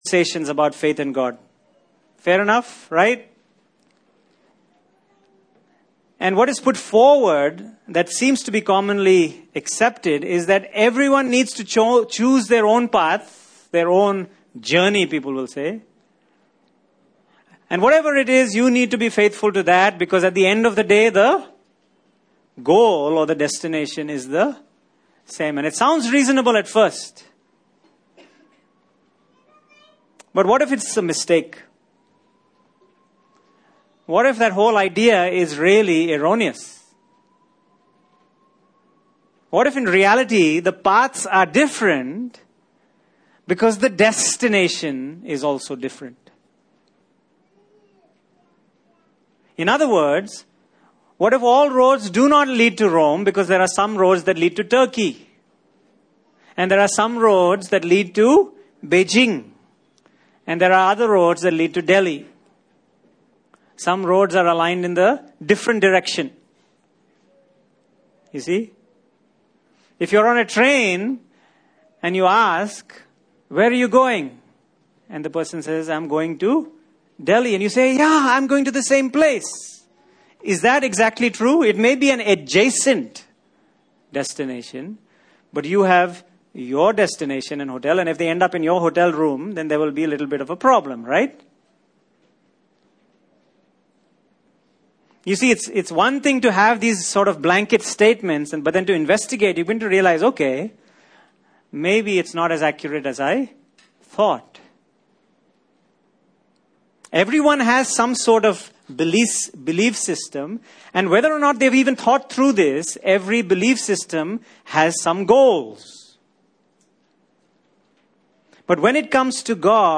Passage: Romans 4:25 Service Type: Resurrection Sunday